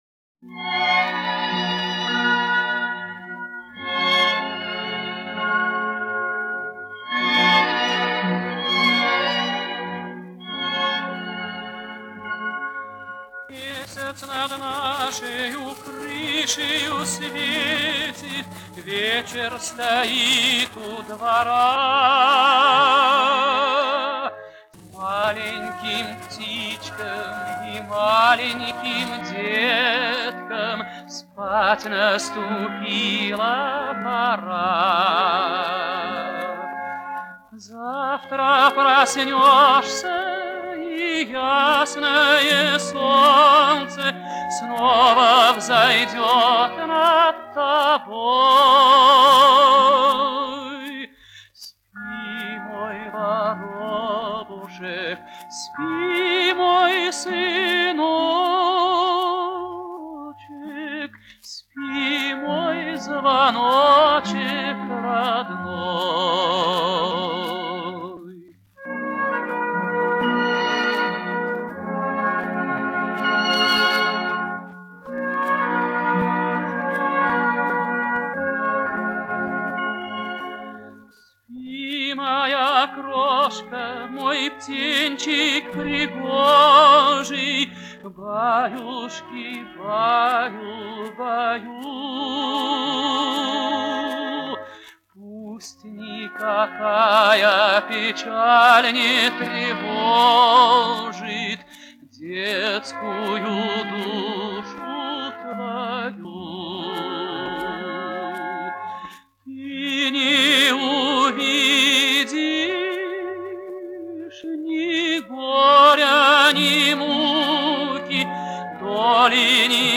Повышение качества.